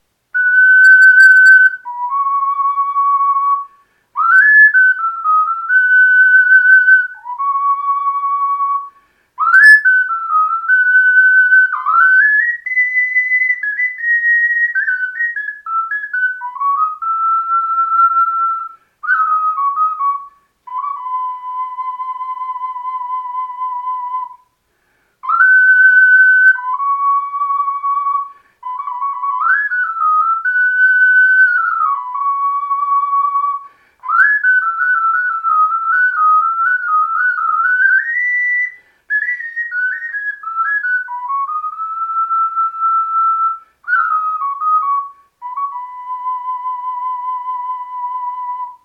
C Soprano Ocarina (transverse by Focalink):
Unidentified Song, not written by the author but played by him